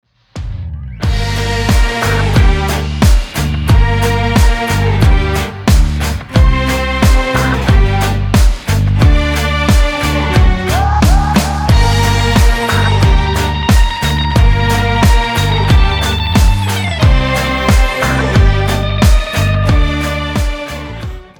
• Качество: 320, Stereo
спокойные
без слов
русский рок